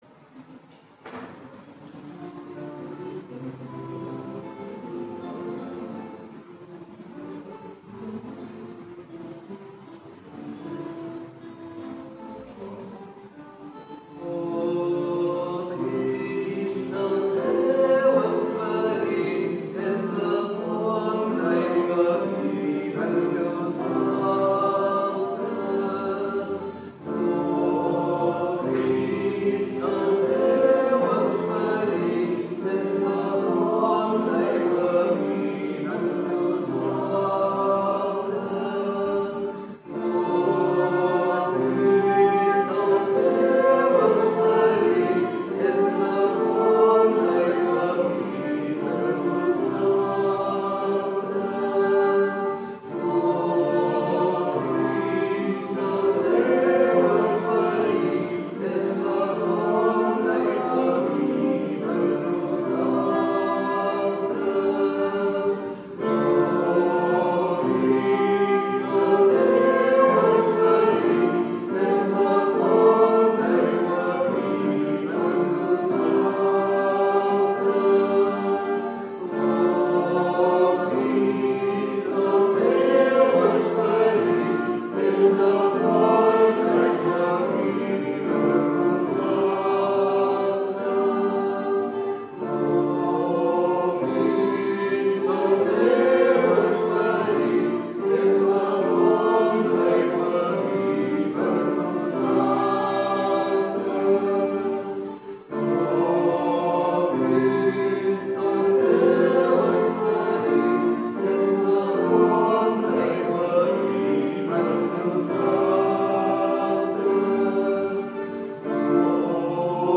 Pregària de Taizé
Parròquia de la Sagrada Família - Diumenge 29 de juny de 2014
Vàrem cantar...